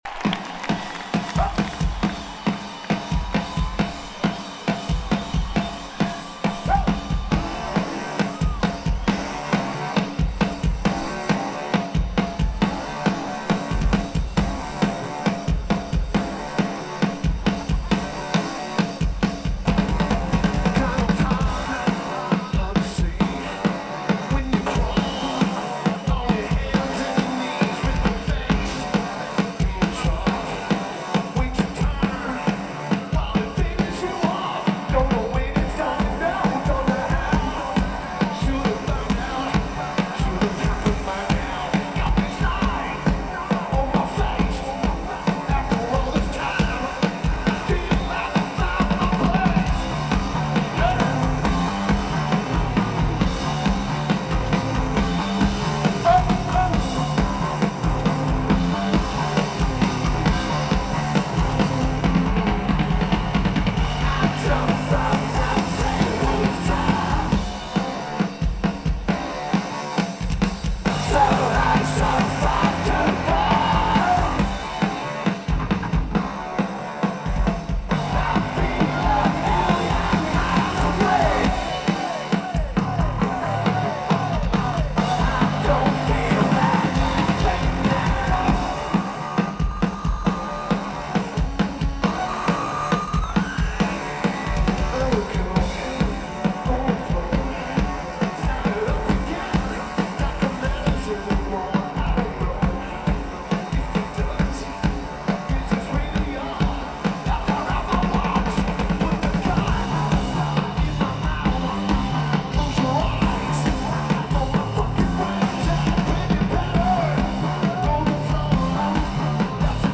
Hollywood Bowl
Lineage: Audio - AUD (AT943 + SP-SPSB-6 + Tascam DR-2D)
Tape sounds great!